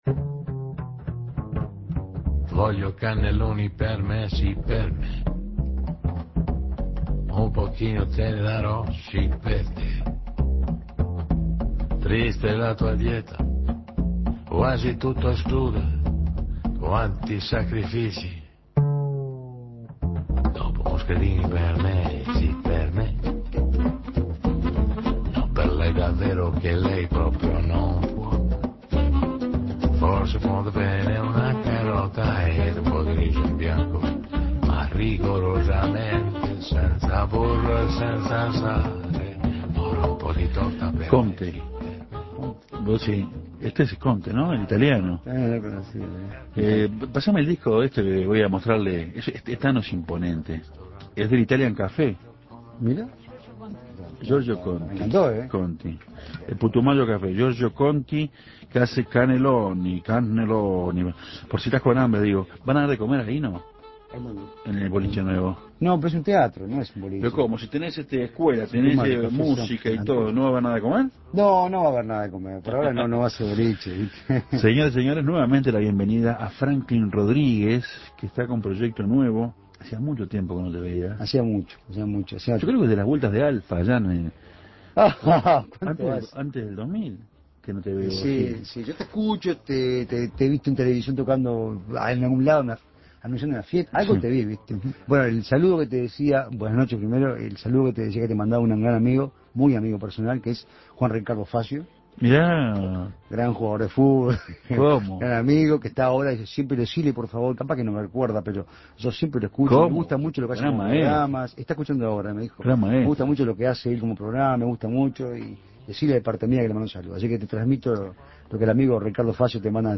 Pasó por el programa y, café de por medio, nos contó sobre sus nuevos proyectos.